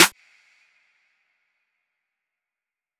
Metro Snares [Workin].wav